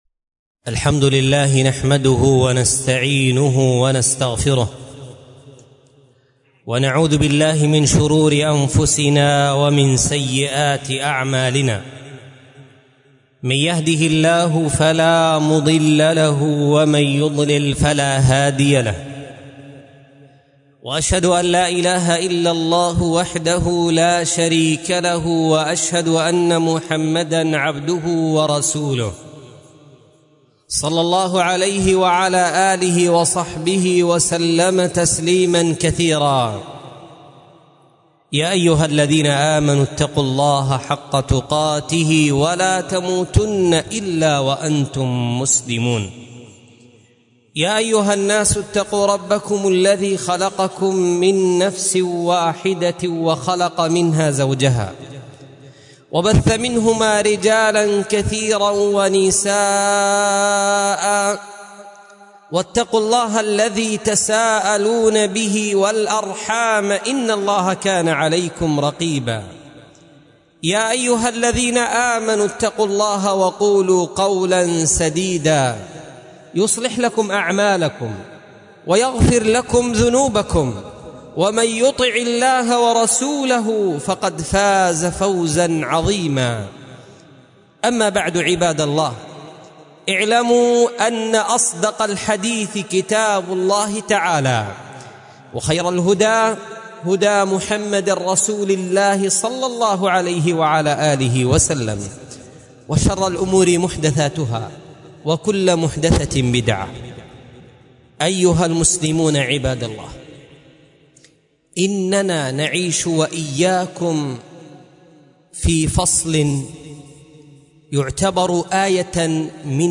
مسجد آل عمران / مصر القاهرة / 6 رجب 1447